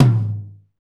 Index of /90_sSampleCDs/Northstar - Drumscapes Roland/KIT_R&B Kits/KIT_R&B Dry Kitx
TOM R B M0UR.wav